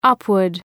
Προφορά
{‘ʌpwərd}
upward.mp3